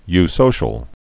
(ysōshəl)